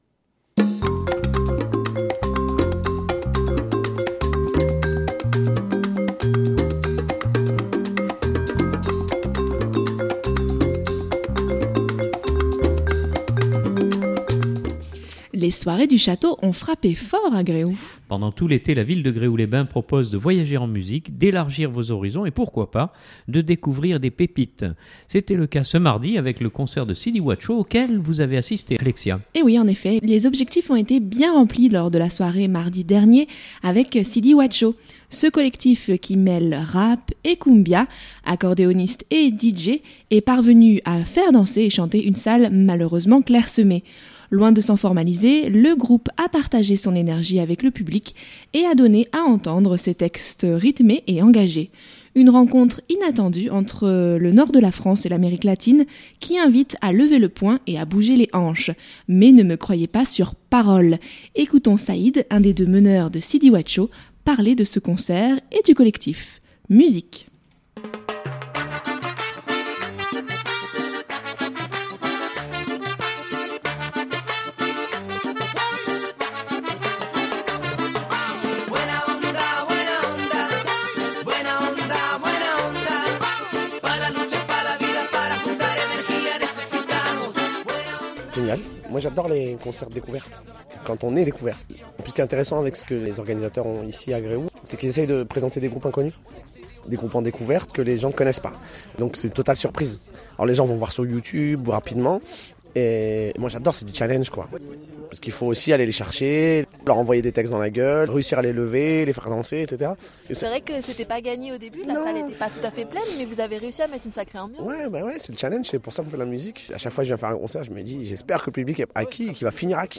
Les objectifs ont bien été remplis lors de la soirée mardi dernier avec Sidi Wacho. Ce collectif qui mêle rap et cumbia, accordéoniste et DJ est parvenu à faire danser et chanter une salle malheureusement clairsemée.